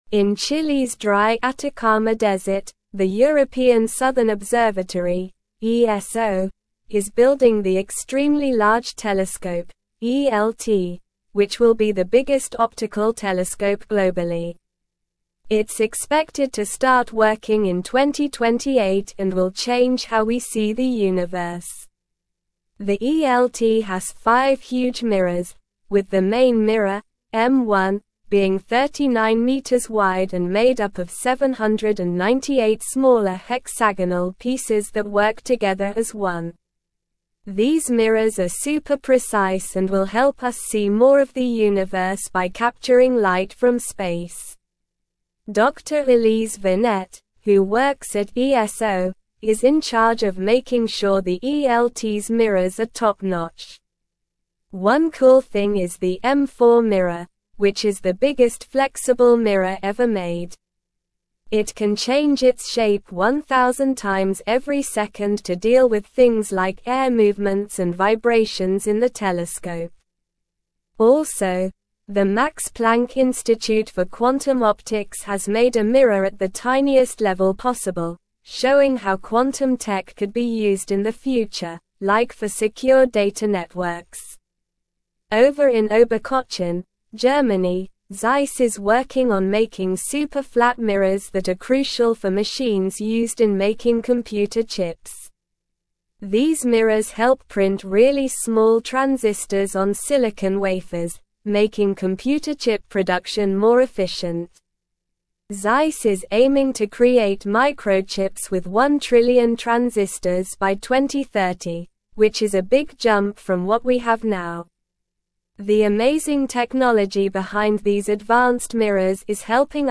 Slow
English-Newsroom-Upper-Intermediate-SLOW-Reading-Advancing-Technology-Through-Precision-The-Power-of-Mirrors.mp3